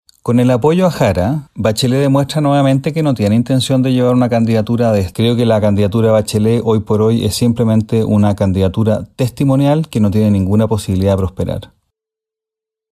Fue el senador y miembro de la Comisión de Relaciones Exteriores, Rojo Edwards, quien cuestionó a la expresidenta al no mantener una “candidatura de Estado”, agregando que no tiene ninguna posibilidad de prosperar.